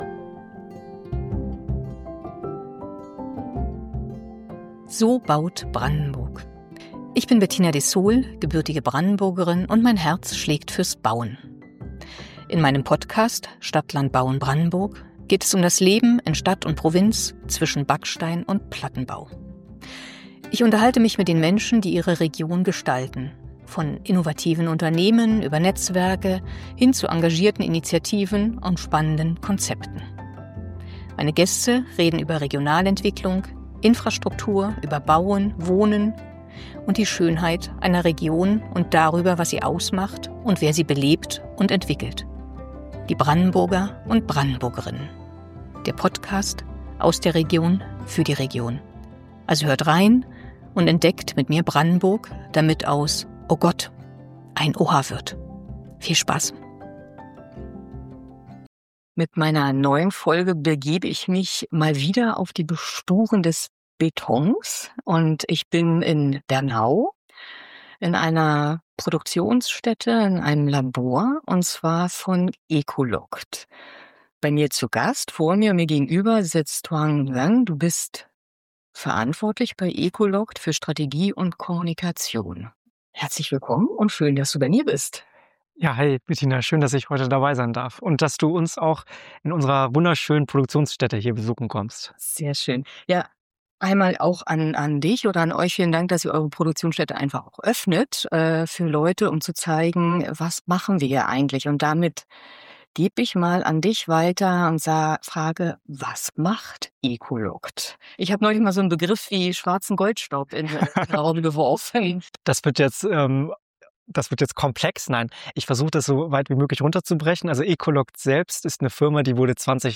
Mich zog es nach Bernau in die Produktionshalle und das Labor von ecoLocked.